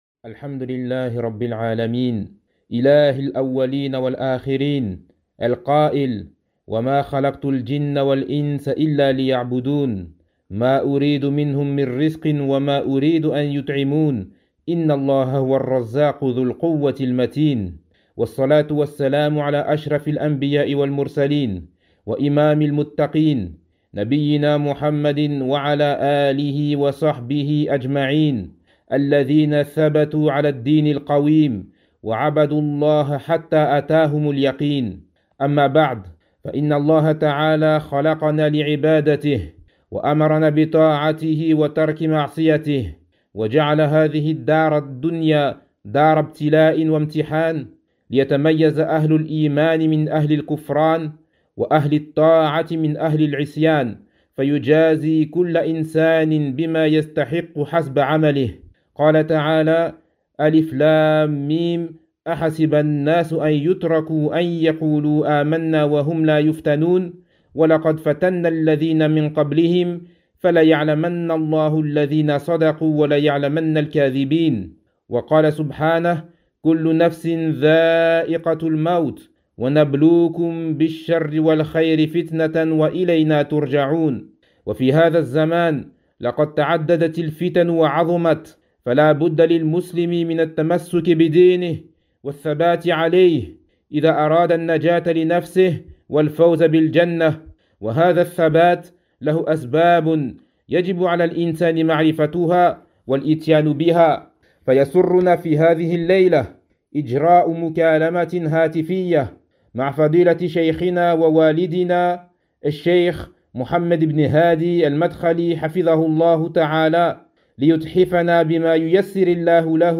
كلمة توجيهية بعنوان : أسباب الثبات على الدين
ألقاها فضيلة الشيخ عبر الهاتف بعد عشاء الأحد ٣٠ رجب ١٤٤٢هـ بالمدينة النبوية.